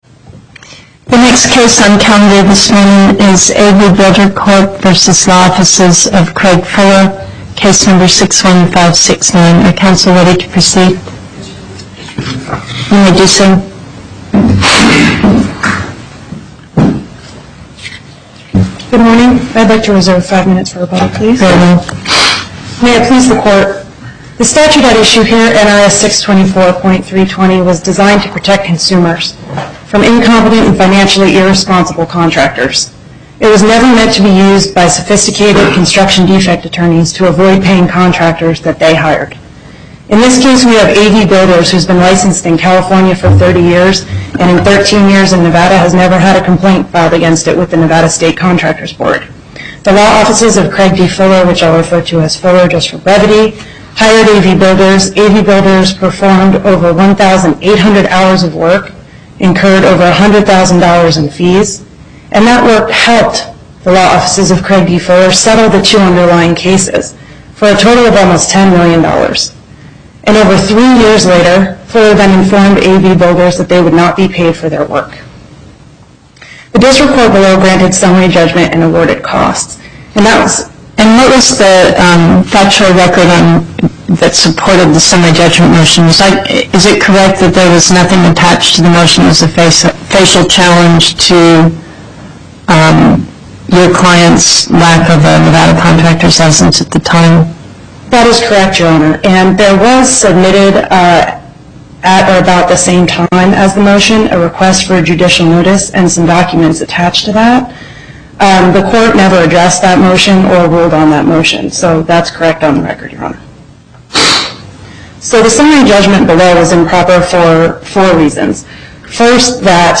Location: Las Vegas Before the Northern Nevada Panel, Justice Pickering Presiding